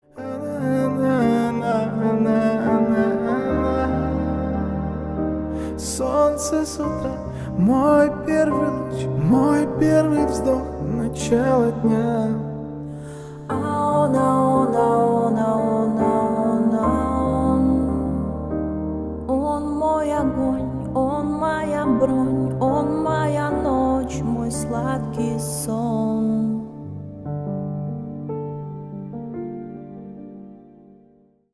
Поп Музыка
кавер # спокойные